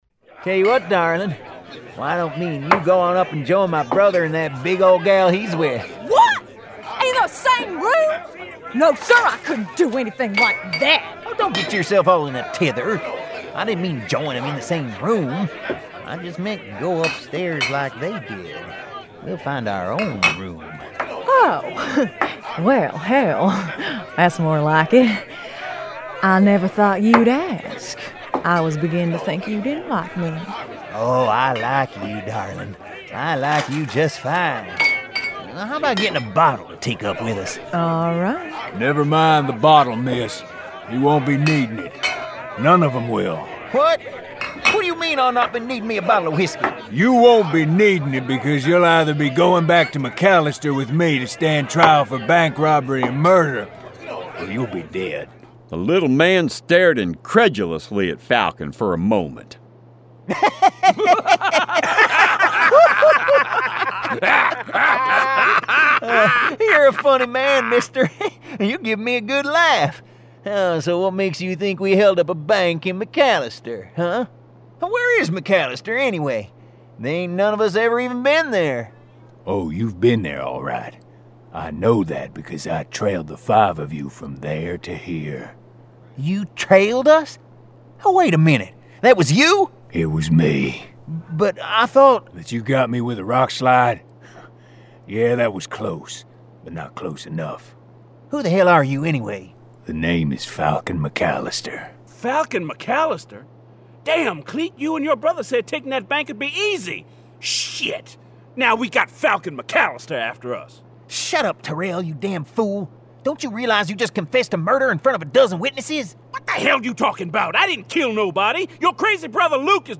Full Cast. Cinematic Music. Sound Effects.
[Dramatized Adaptation]
Genre: Western
Additional Original Music